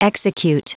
Prononciation du mot execute en anglais (fichier audio)
Prononciation du mot : execute